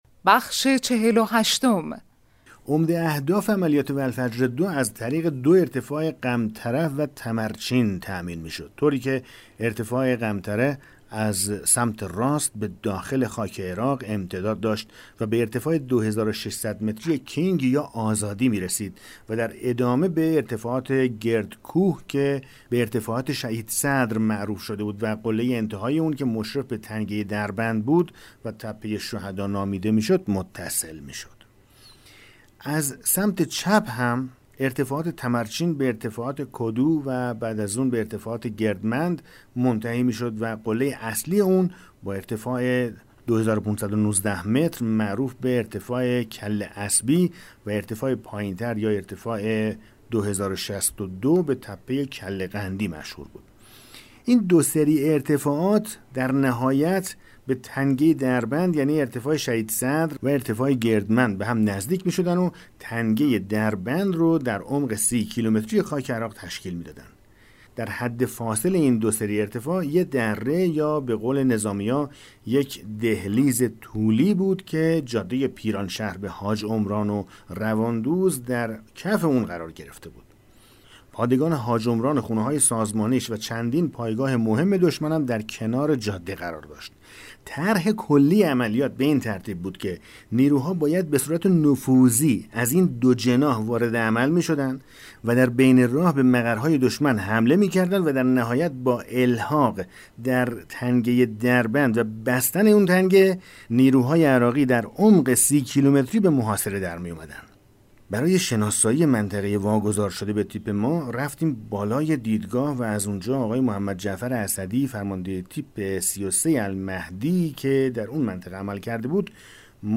کتاب صوتی پیغام ماهی ها، سرگذشت جنگ‌های نامتقارن حاج حسین همدانی /قسمت 48